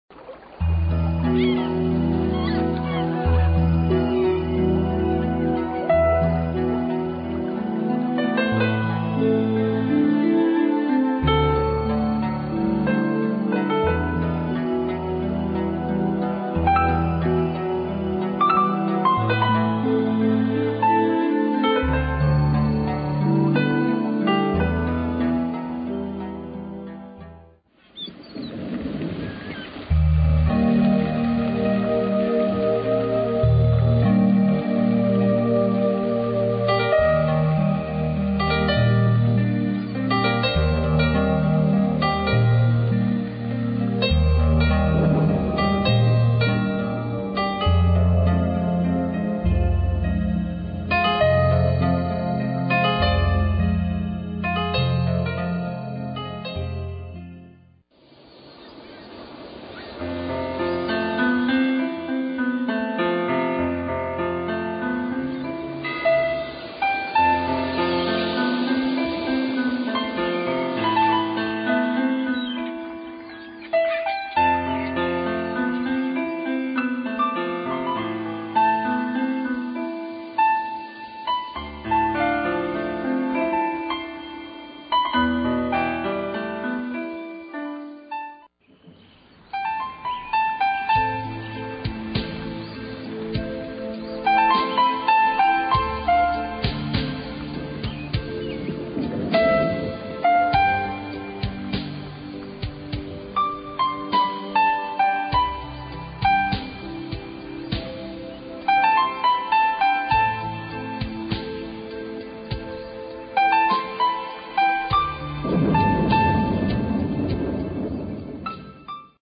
Environmental sounds including ocean waves, rainforest, nature at night, and rain.